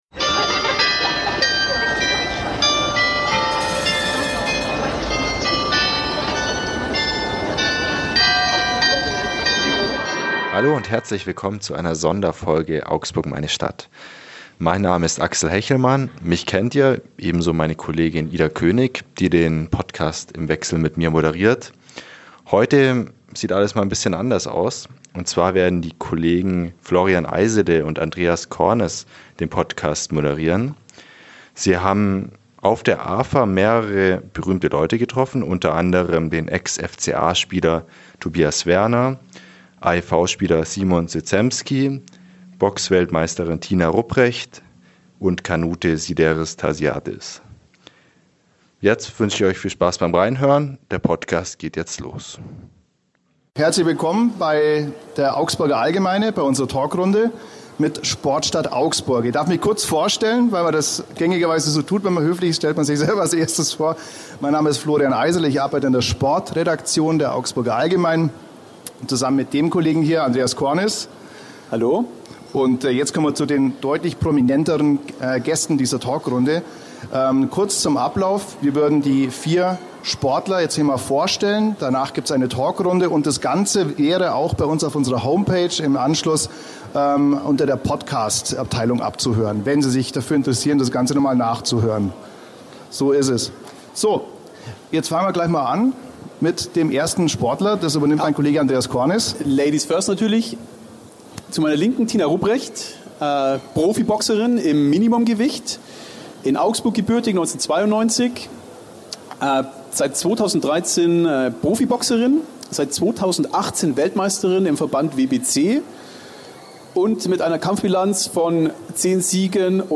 FCA, AEV, Boxen, Kanu: Vier Top-Sportler im Gespräch ~ Augsburg, meine Stadt Podcast